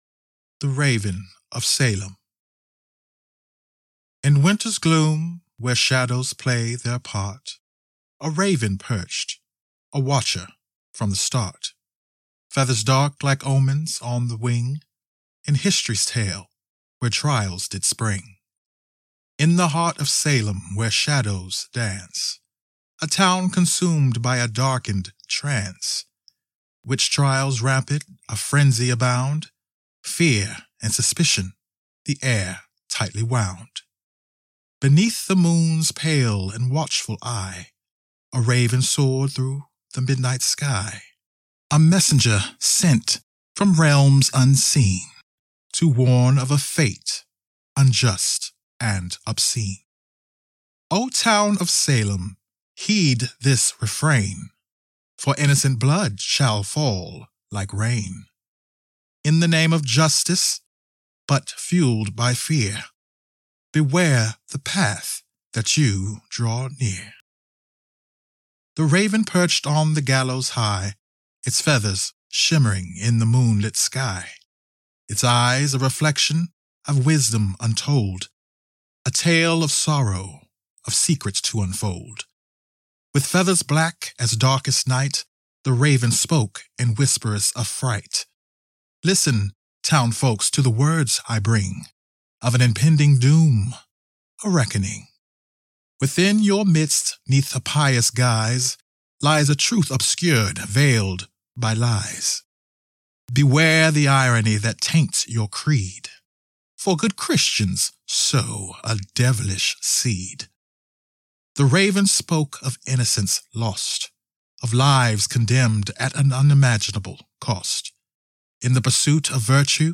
The Raven of Salem - Poem, British accent,
I record in my home studio (RODE Podmic & Ebxya interface) and send the files to you in the format of your choice.